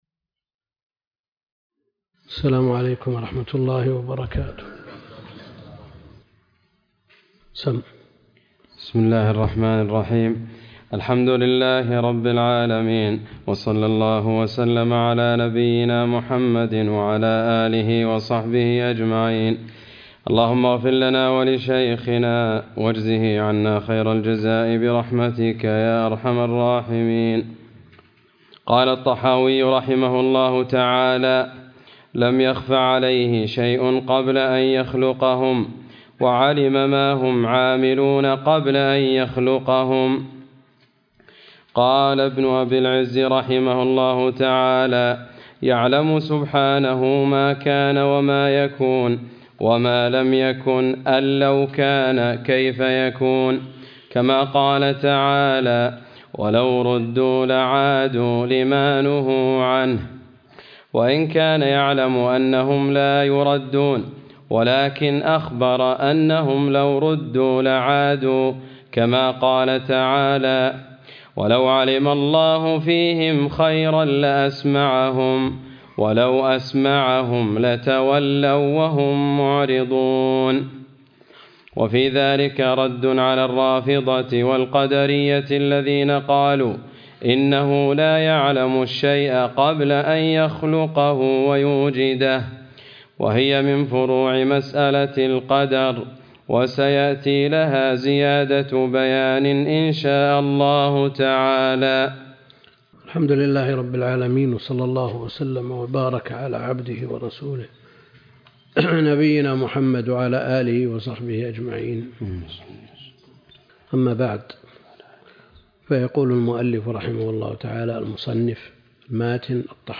عنوان المادة الدرس (15) شرح العقيدة الطحاوية تاريخ التحميل السبت 21 يناير 2023 مـ حجم المادة 24.48 ميجا بايت عدد الزيارات 208 زيارة عدد مرات الحفظ 103 مرة إستماع المادة حفظ المادة اضف تعليقك أرسل لصديق